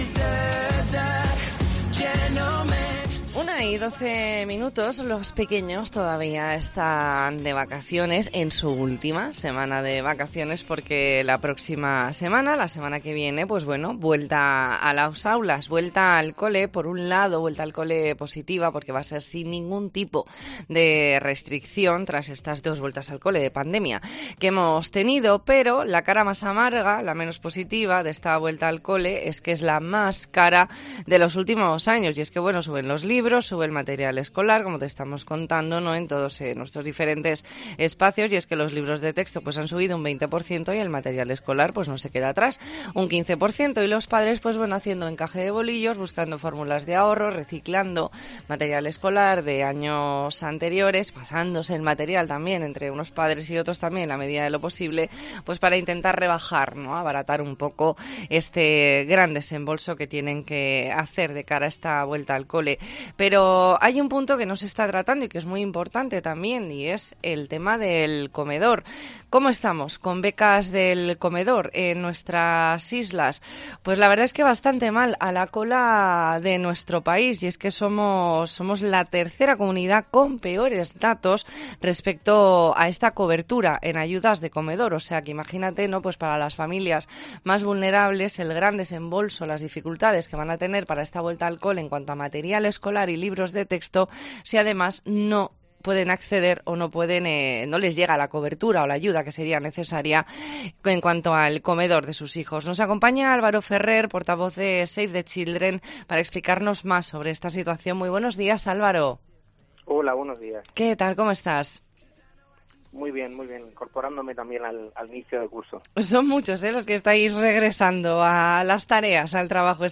ntrevista en La Mañana en COPE Más Mallorca, lunes 5 de septiembre de 2022.